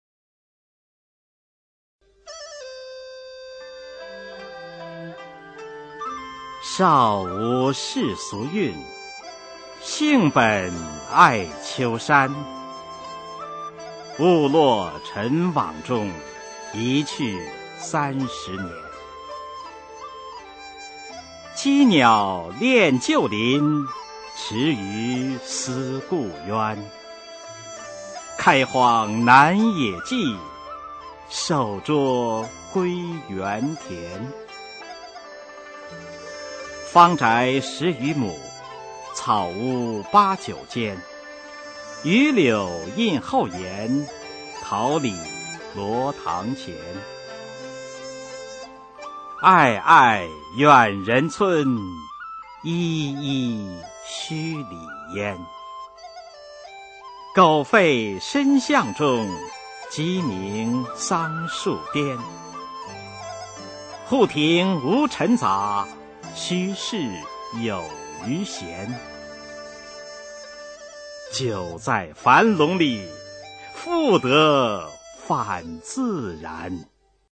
[魏晋诗词诵读]陶渊明-归园田居（男） 古诗朗诵